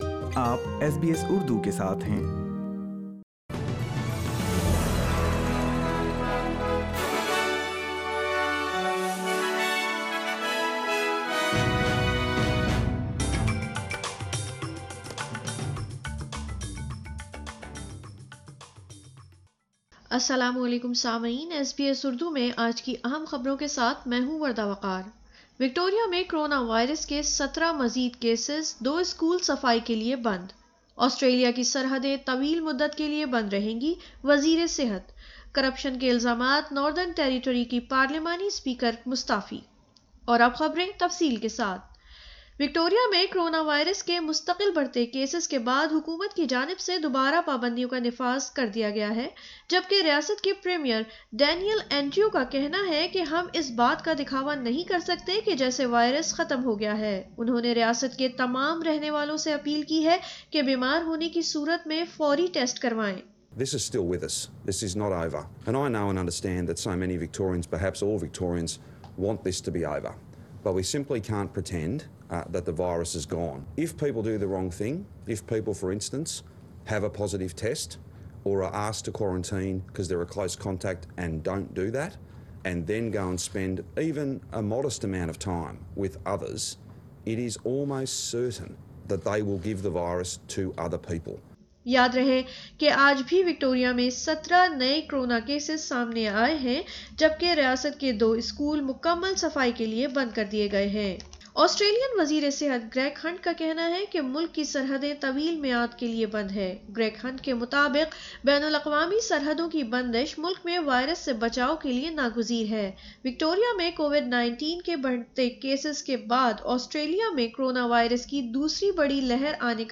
اردو خبریں 23 جون 2020